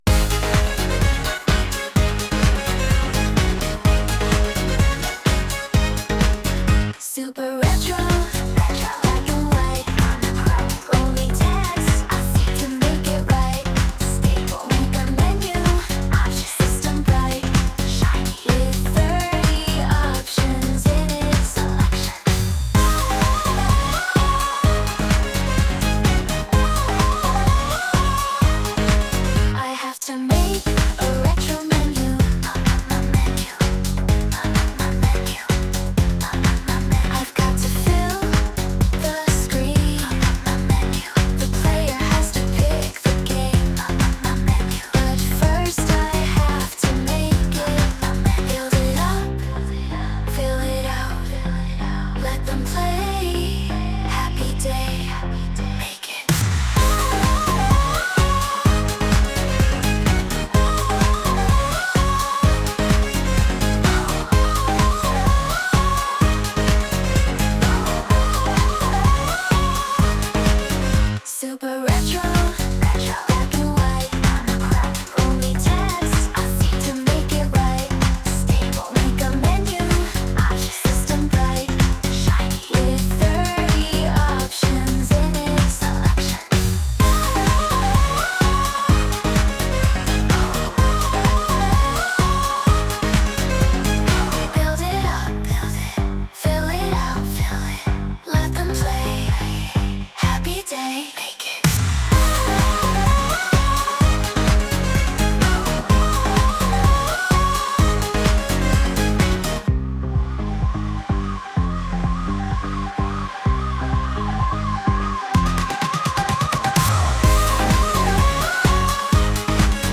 This one was generated using Suno's "Cover" feature, but it took the chord structure in a really odd direction.
Sung by Suno